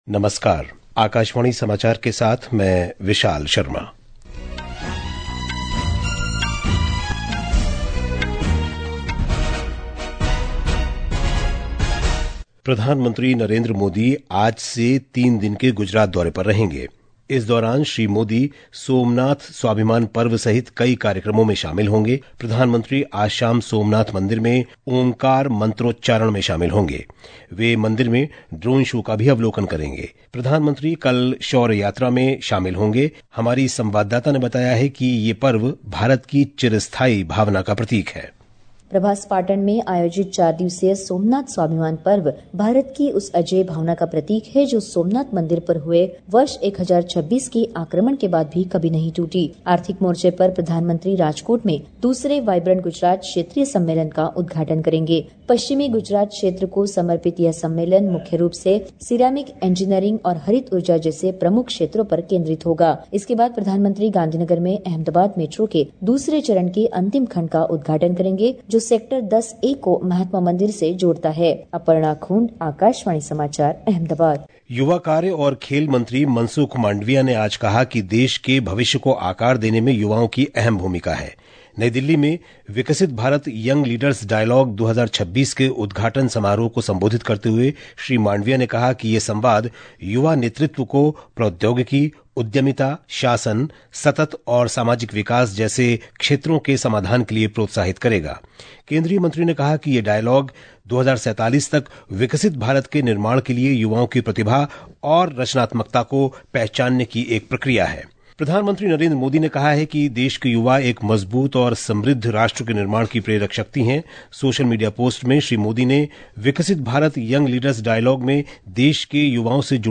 National Bulletins
प्रति घंटा समाचार | Hindi